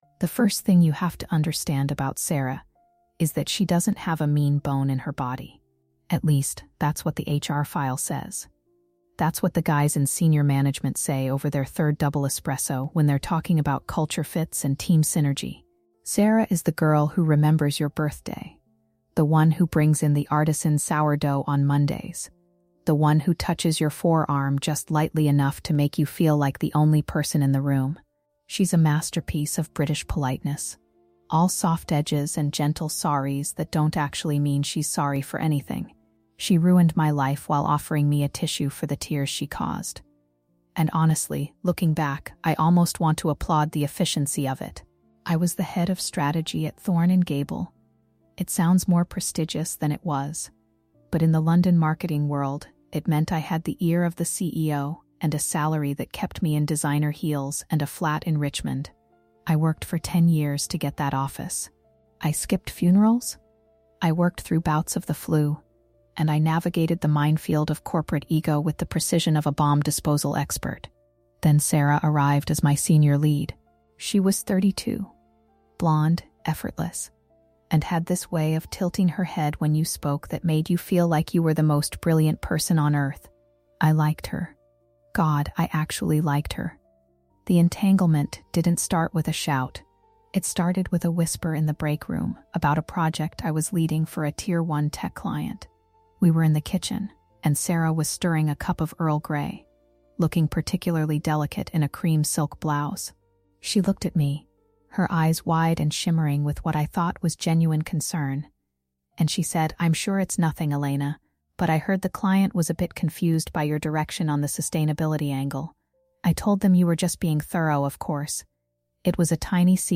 In this episode of WORKPLACE ENTANGLEMENT, we dive into a chilling case of corporate sabotage where the most dangerous weapon wasn’t a scandal or a lawsuit, but a polite smile. Our narrator, a high-achieving Head of Strategy at a top London marketing firm, shares the harrowing story of her professional downfall at the hands of a subordinate who mastered the art of "weaponized kindness."